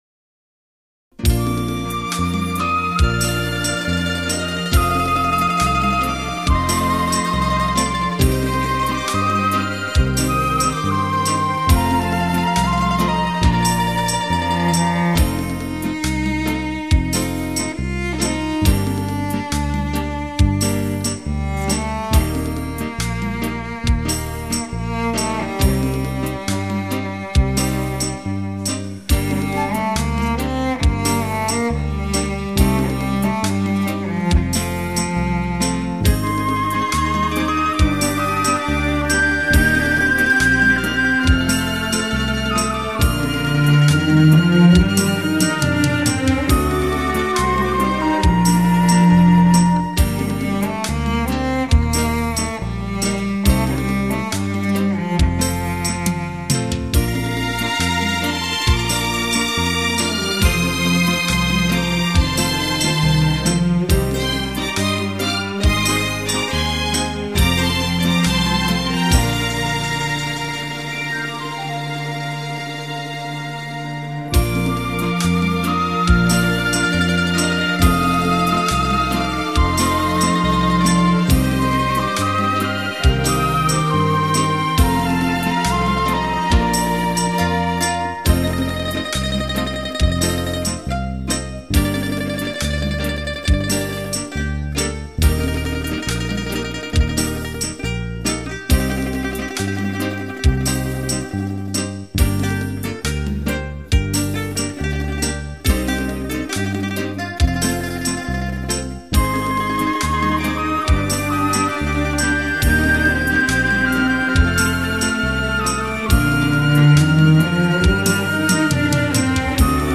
乐队由西洋管弦乐与中国民族乐器混合组成。